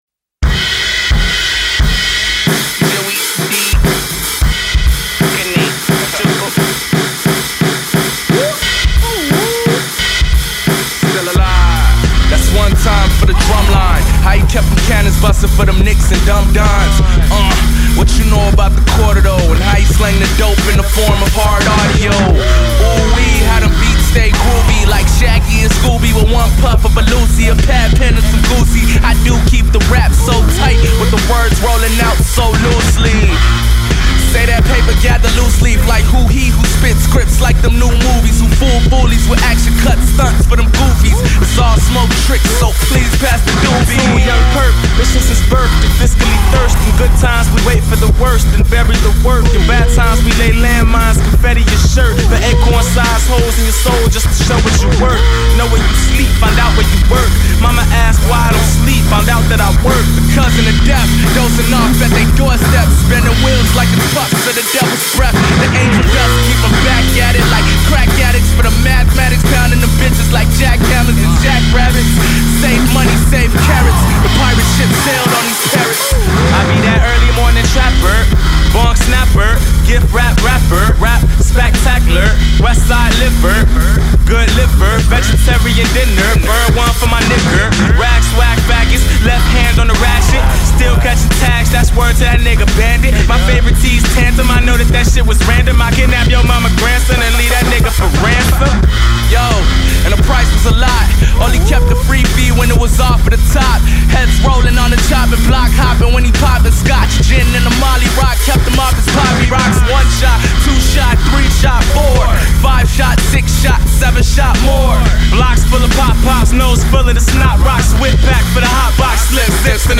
raps
bass